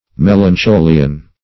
Search Result for " melancholian" : The Collaborative International Dictionary of English v.0.48: Melancholian \Mel`an*cho"li*an\, n. A person affected with melancholy; a melancholic.